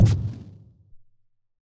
energy_projectile_hit.ogg